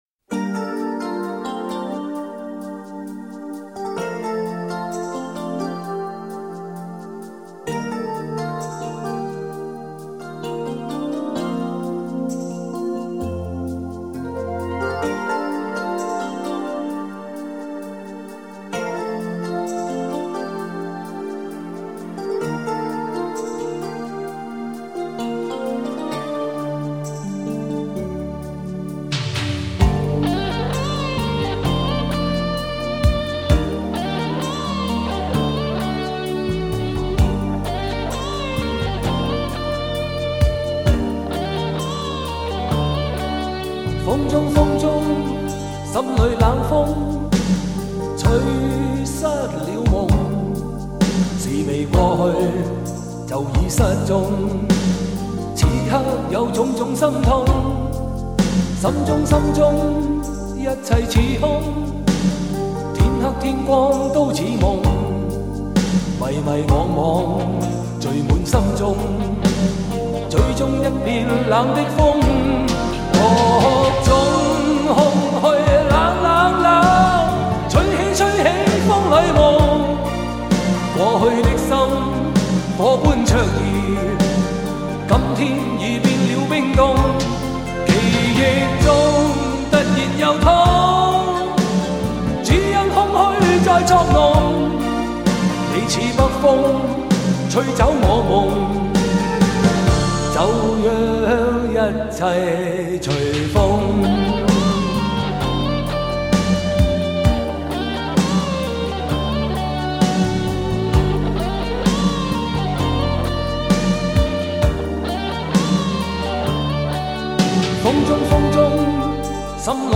雄伟典范乐曲 必唯天作之合Hi-Fi典范 极致人声
高密度24BIT数码录音